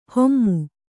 ♪ hommu